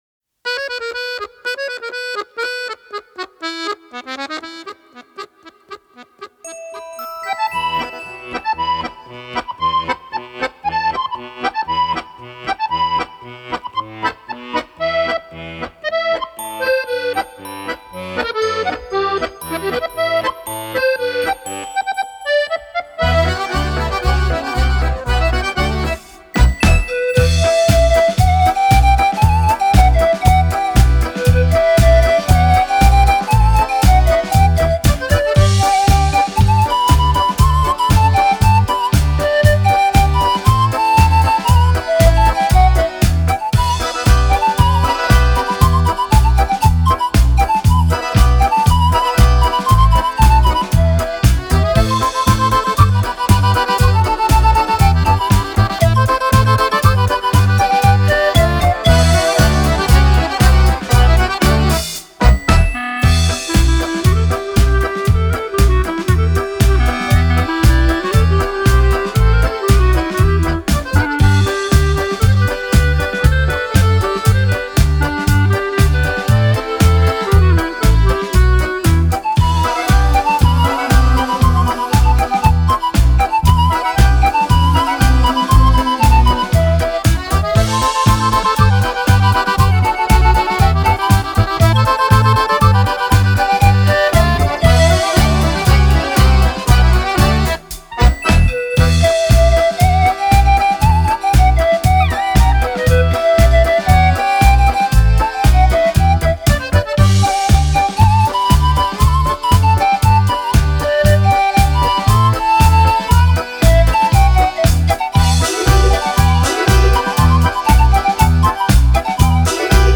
Жанр: Easy Listening, Instrumental, Panflute